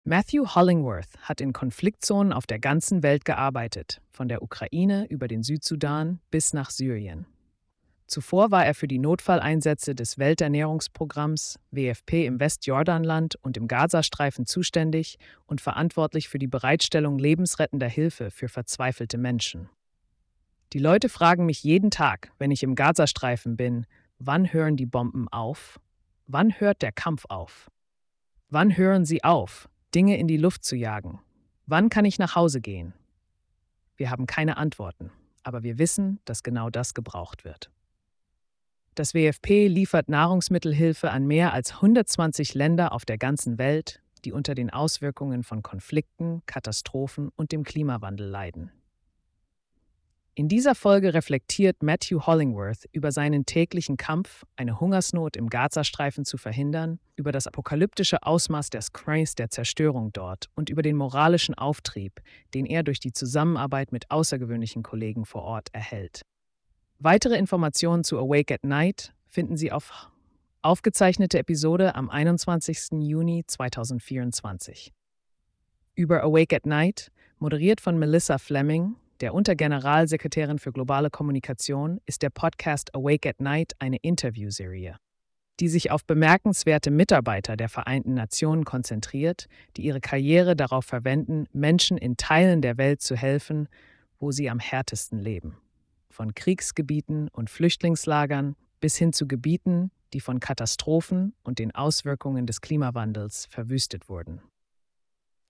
Wettlauf gegen den Hunger in Gaza - Interview mit dem Welternährungsprogramm | Nachts wach | Vereinte Nationen
Moderiert von Melissa Fleming, der Unter-Generalsekretärin für Globale Kommunikation,‍ ist der Podcast ‚Awake at Night‘ eine Interviewserie,‌ die sich auf bemerkenswerte Mitarbeiter ‌der Vereinten Nationen konzentriert, die ihre Karriere darauf verwenden, Menschen in Teilen der Welt zu helfen, wo sie am härtesten leben – von Kriegsgebieten und Flüchtlingslagern bis hin zu Gebieten, die von Katastrophen und den Auswirkungen des Klimawandels verwüstet wurden.